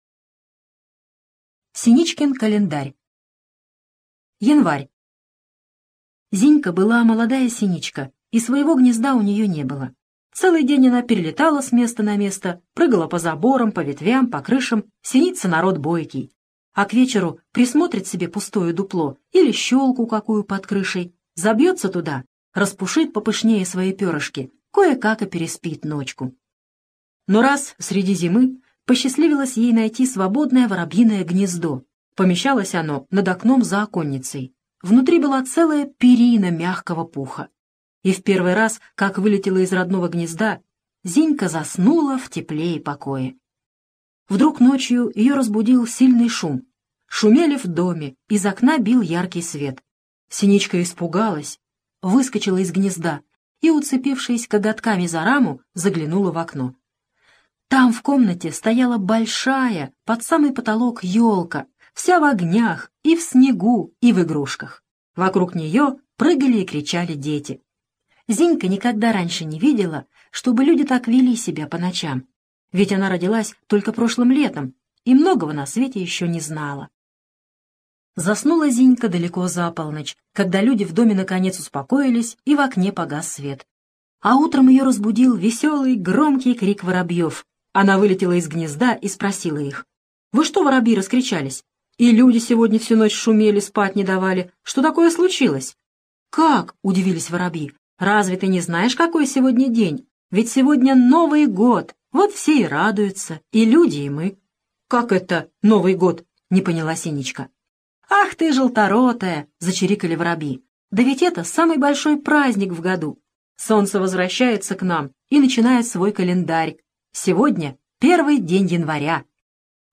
Синичкин календарь - аудио рассказ Бианки - слушать онлайн